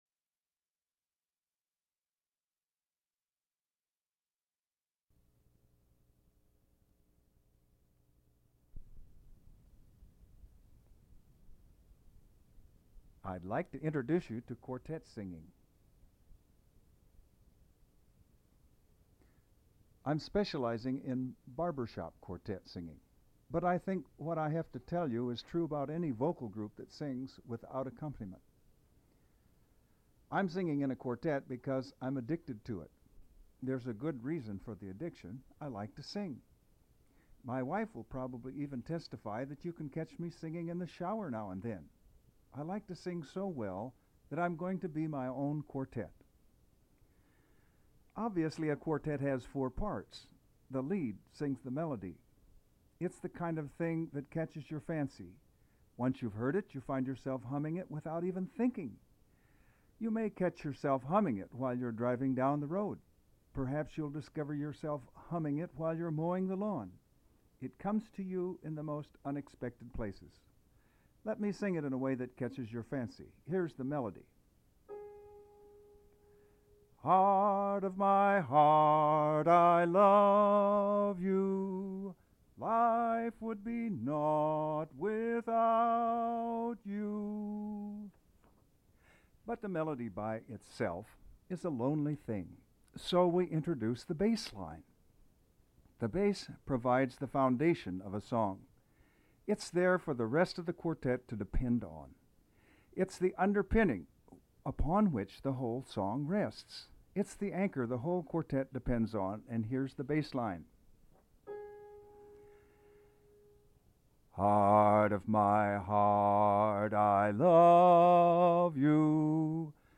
At that point I took it upon myself to learn all 4 parts to HEART OF MY HEART, a classic Barbershop love song.
I went to a local recording studio, expanded on the above context, recorded a contextual statement and proceeded to record each part of the song—Lead; Bass; Baritone, and; Tenor.
Once I had made the separate recordings the studio technician put all 5 together on one tape.
as a quartet